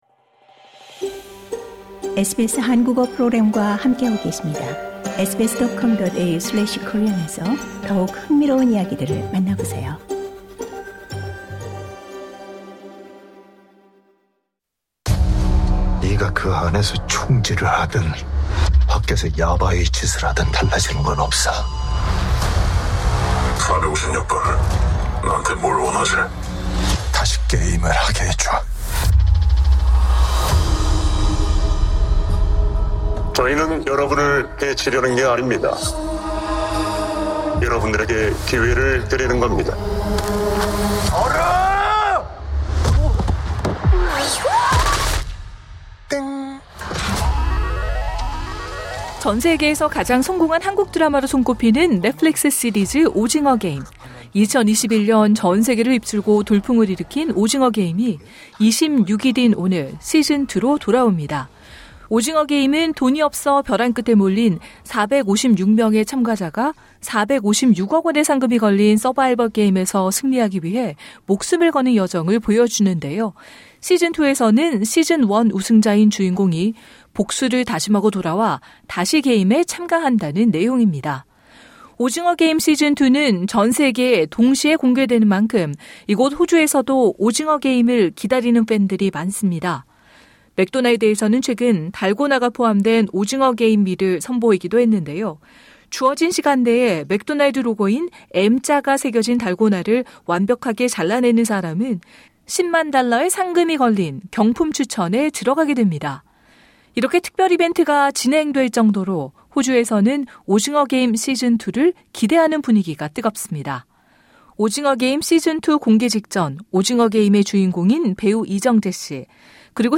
이병헌 씨의 목소리로 직접 들어 보시죠.